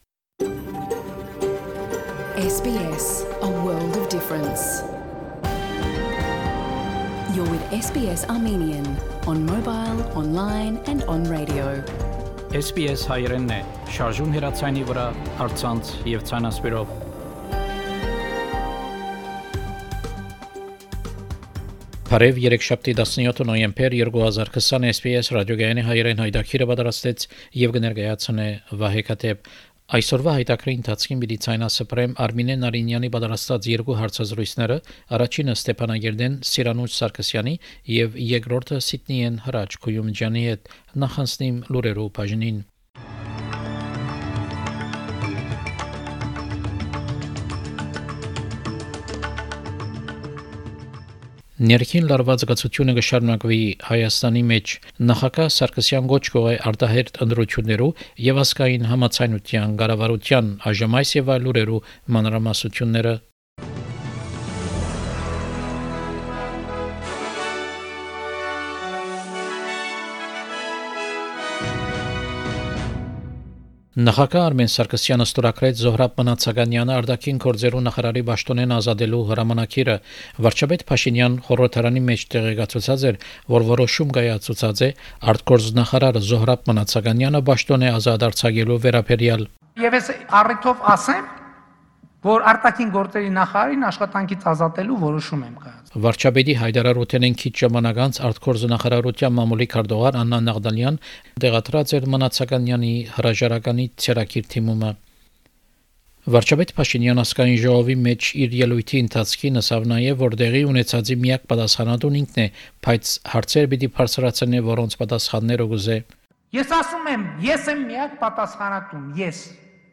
SBS Armenian news bulletin – 17 November 2020
SBS Armenian news bulletin from 17 November 2020 program.